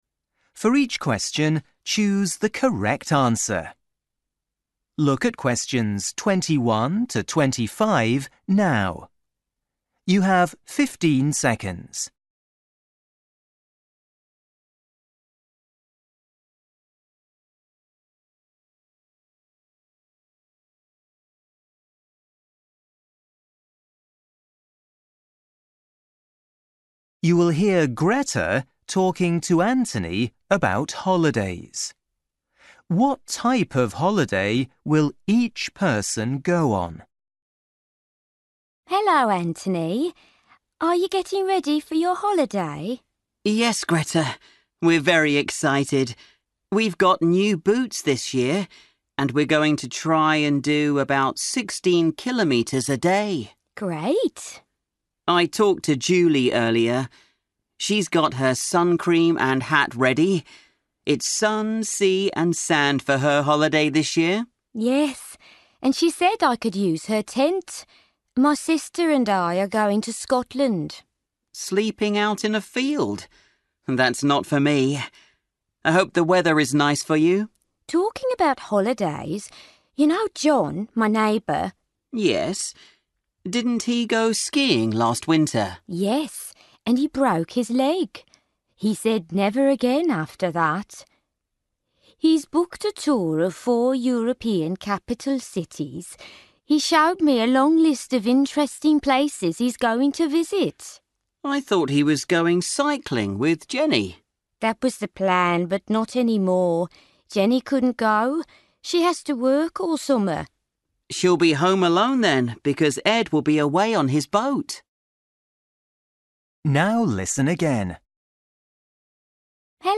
You will hear Greta talking to Anthony about holidays.